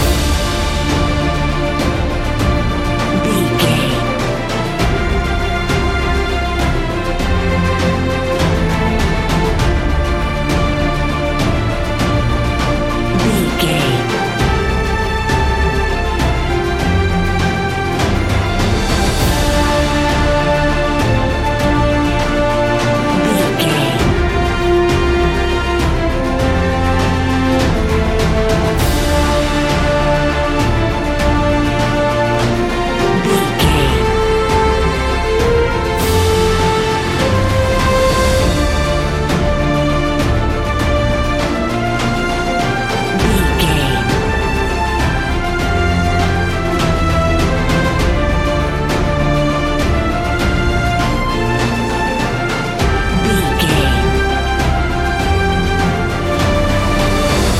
Epic / Action
Uplifting
Aeolian/Minor
energetic
powerful
brass
cello
drums
strings
trumpet
violin